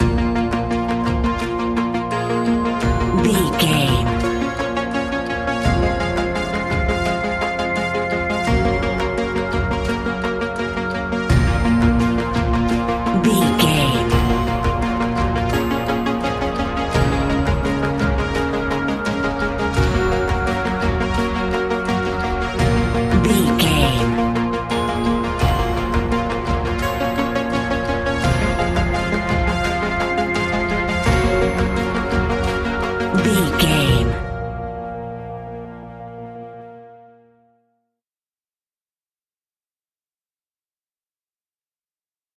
In-crescendo
Thriller
Aeolian/Minor
ominous
dark
haunting
eerie
horror music
Horror Pads
horror piano
Horror Synths